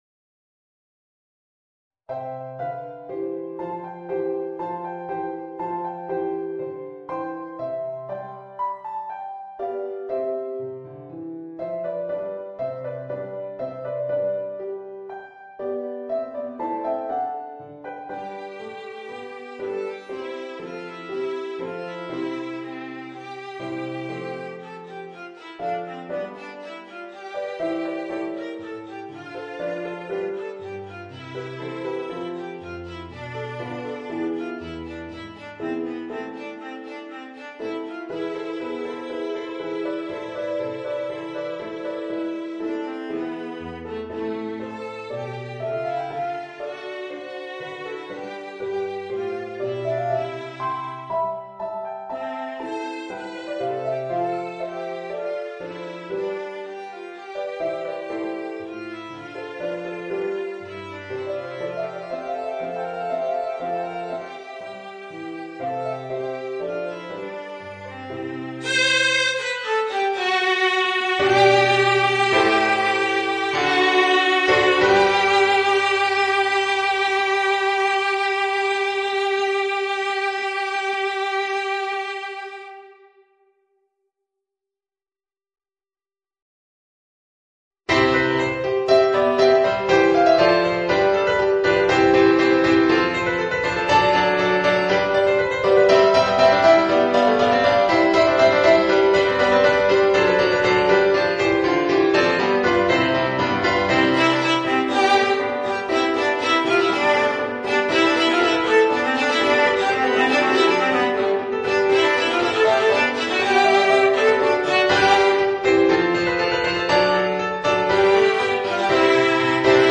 Voicing: Viola and Organ